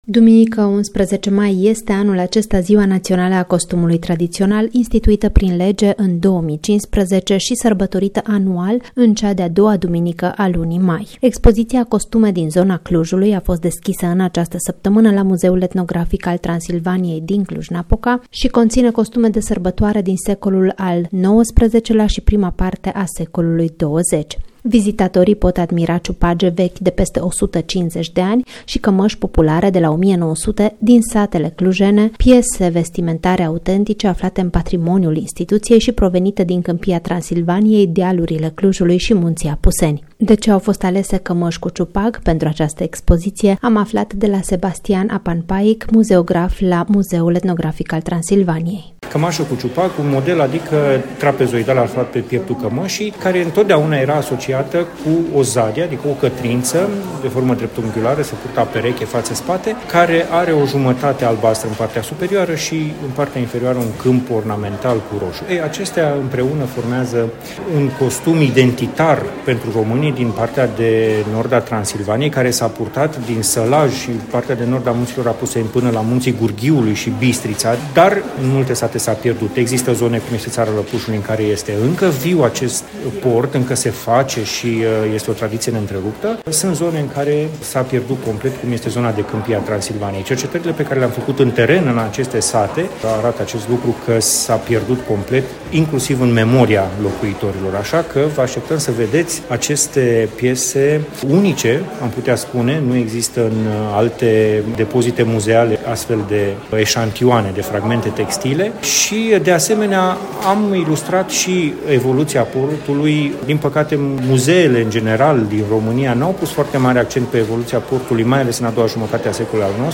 reportaj-expo-costum-popular.mp3